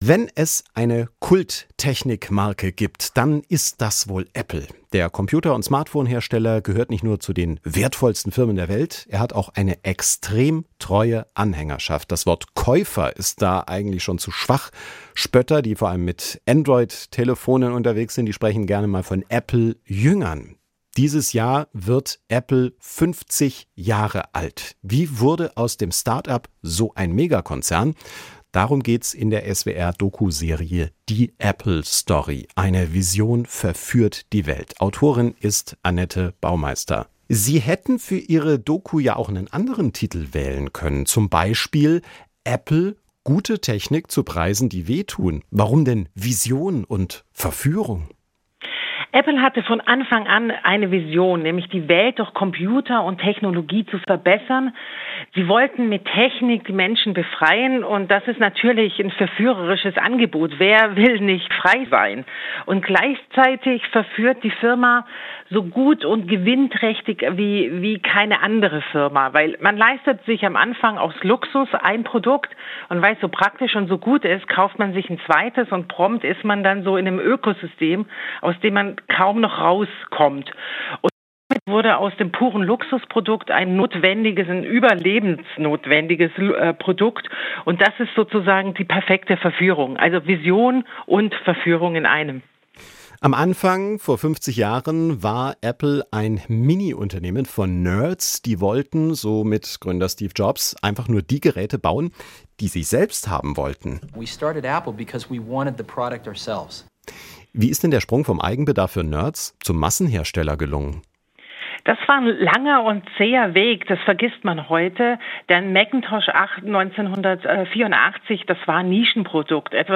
Interviews aus SWR Aktuell als Podcast: Im Gespräch